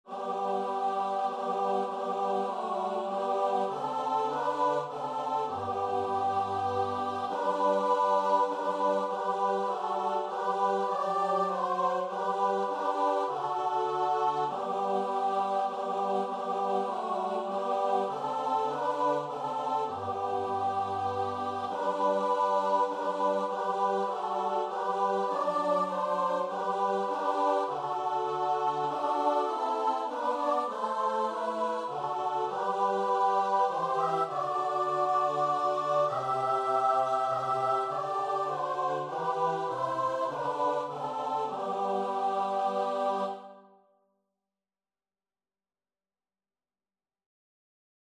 Free Sheet music for Choir (SAB)
3/4 (View more 3/4 Music)
Choir  (View more Intermediate Choir Music)